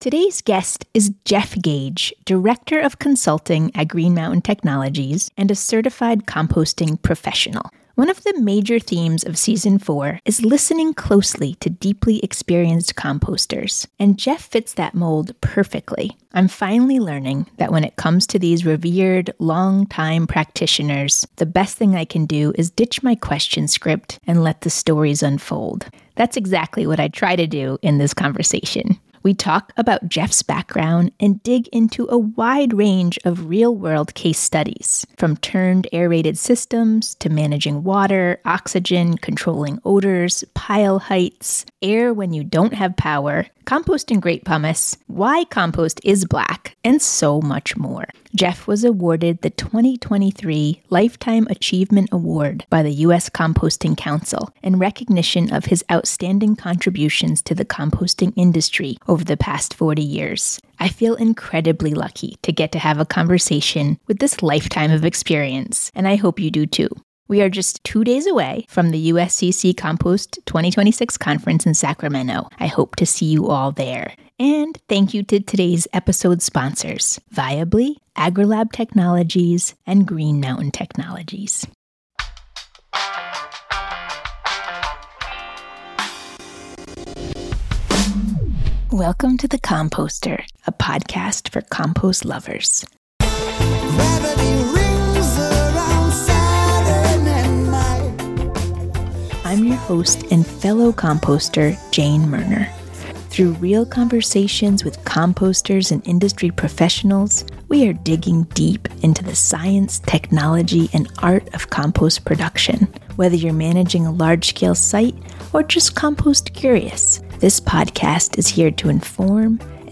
That’s exactly what happened in this conversation.